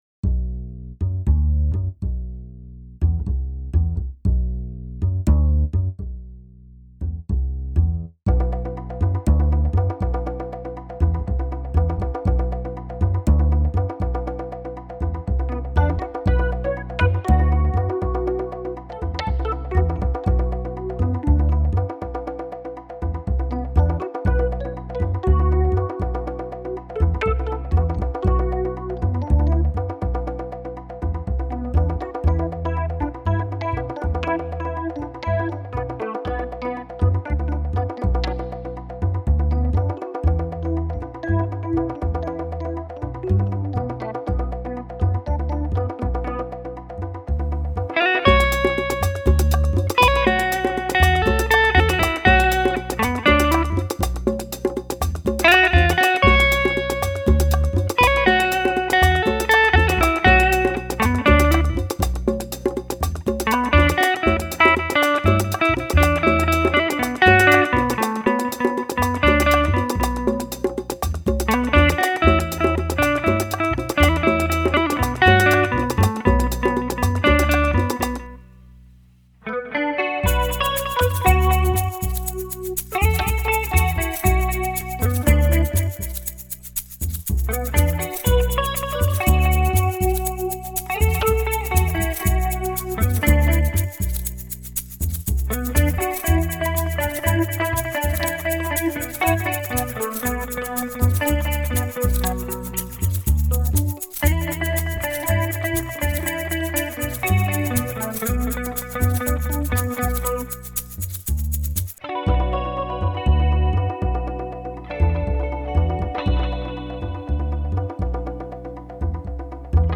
Me he animado y he grabado una versión sencilla…